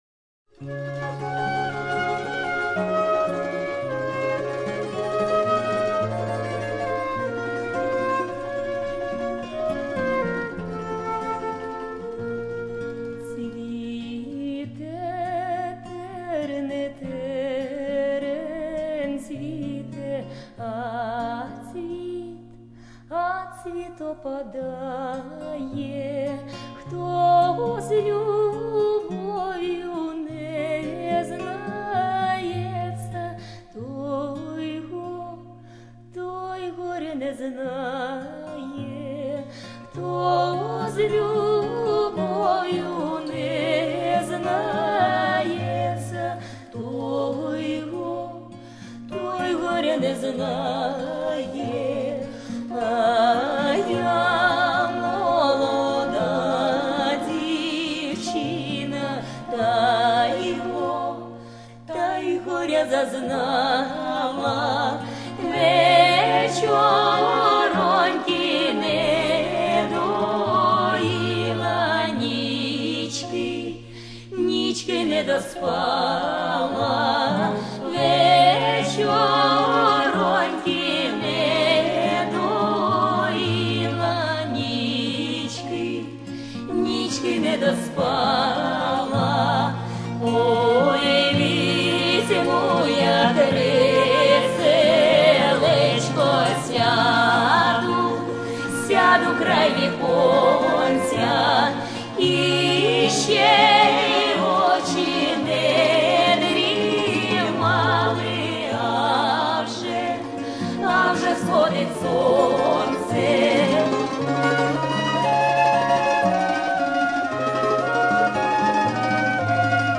Фольклорный театр Забайкалье - Цвите Терен